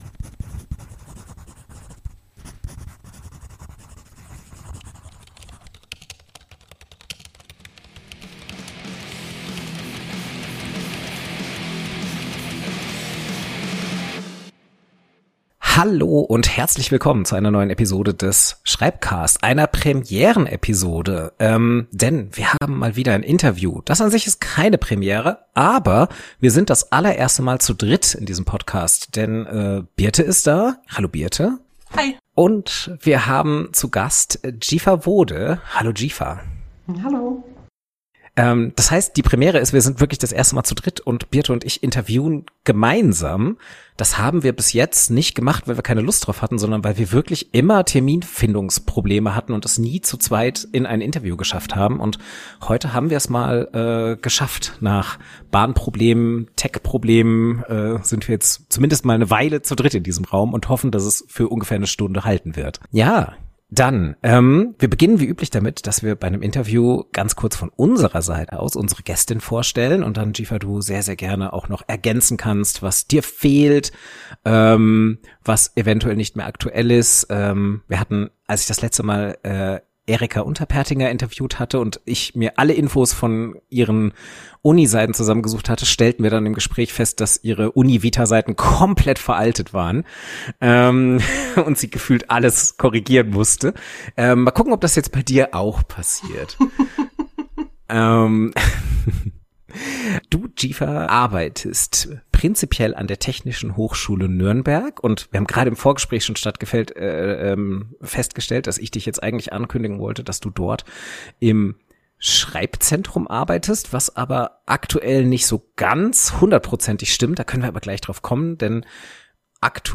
sprechen gemeinsam mit einer Gästin.